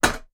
Other Sound Effects
alt-toasterstep2.wav